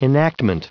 Prononciation du mot enactment en anglais (fichier audio)
Prononciation du mot : enactment